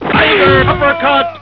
Tiger-uppercut-death.wav